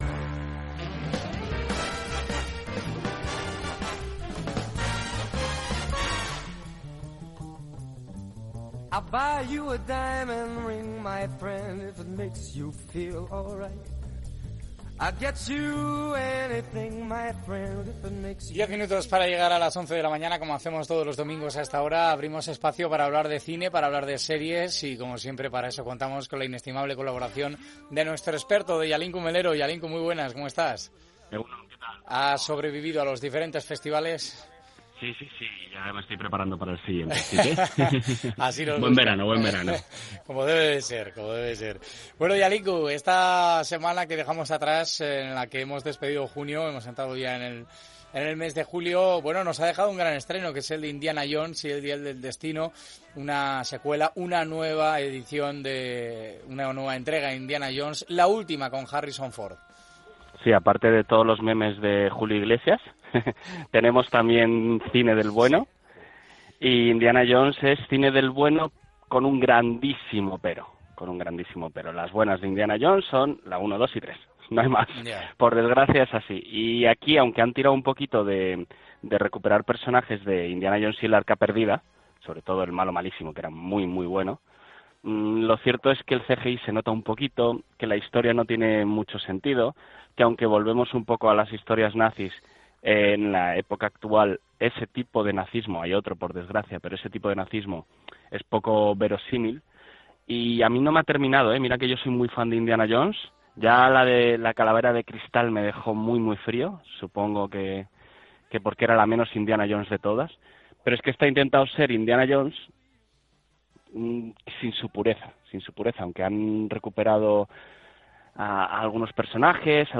Onda Vasca Bizkaia en directo